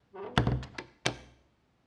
SFX_Door_Close_01.wav